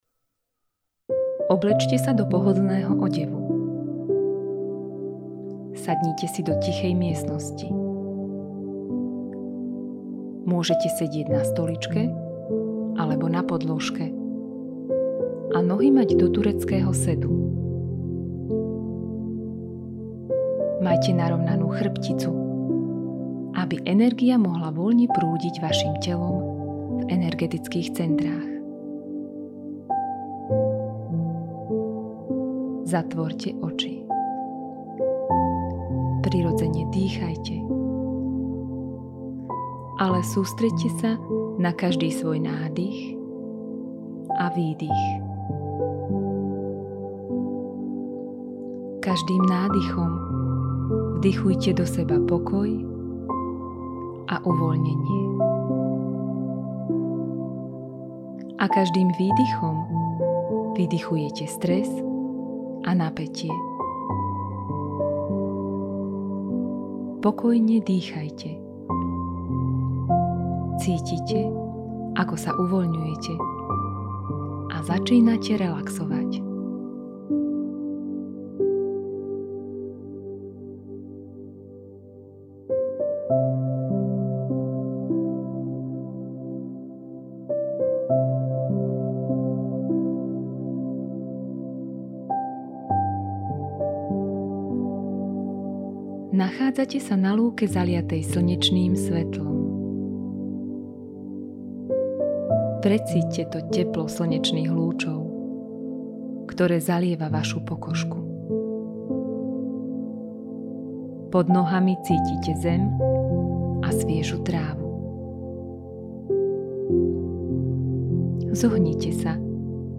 Meditačno-vizualizačné cvičenie Čistenie lona 5.
Meditacne-cvicenie-Cistenie-lona.mp3